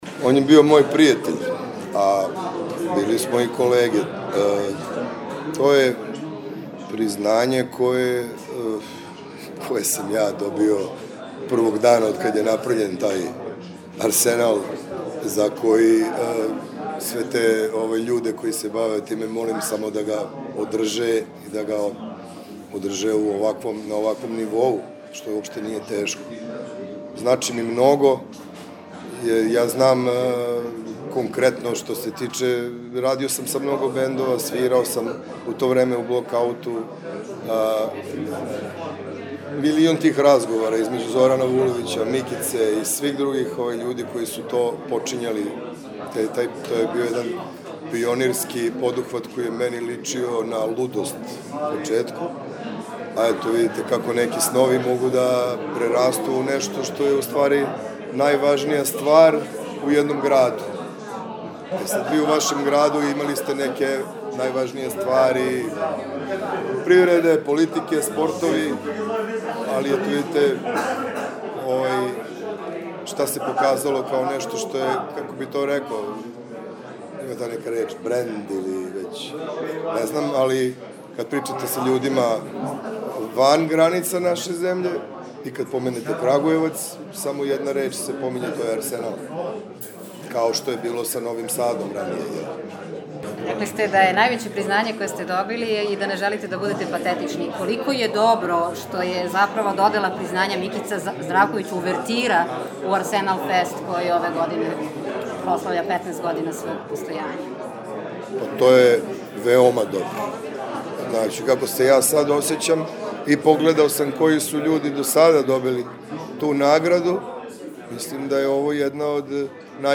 Аудиозапис конференције за медије у Кагујевцу поводом доделе награде „Микица Здравковић“ и 15. издања Арсенал феста које ће бити одржано на Кнежевом арсеналу 26., 27., и 28. јуна 2025.